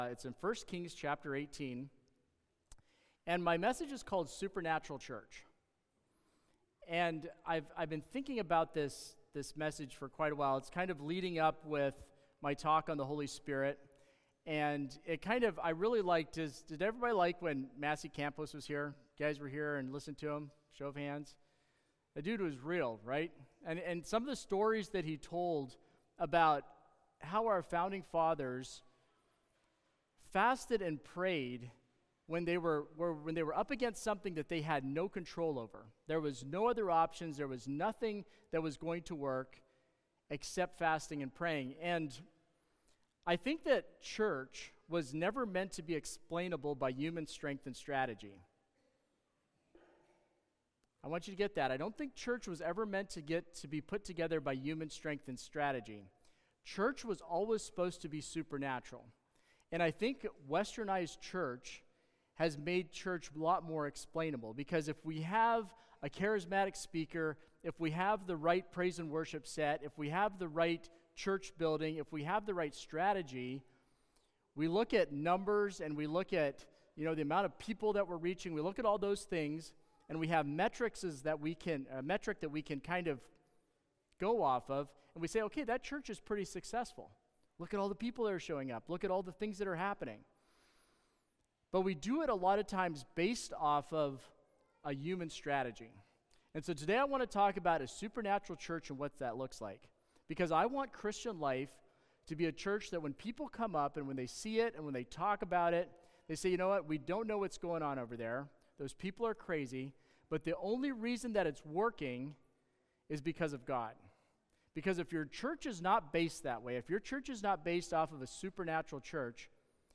Sermons | Christian Life Mennonite